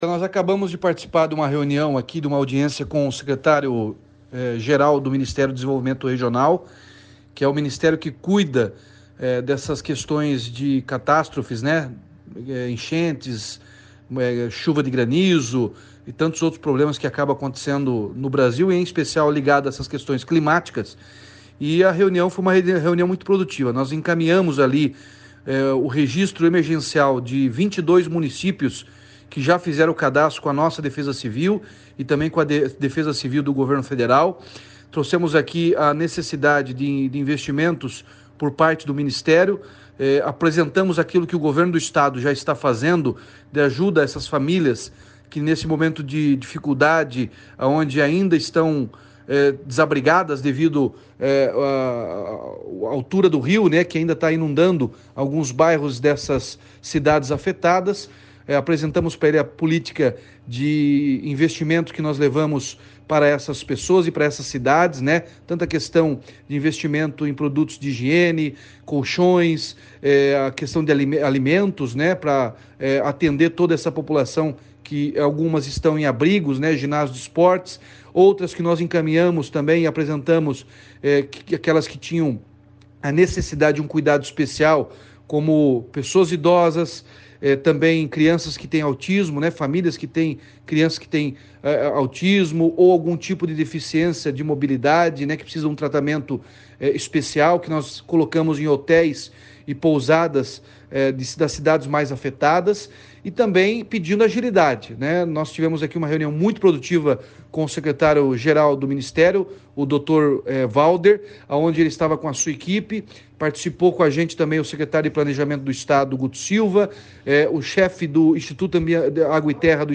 Sonora do governador Ratinho Junior sobre a reunião no Ministério da Integração solicitando ajuda para o atendimento aos afetados pelas chuvas no Paraná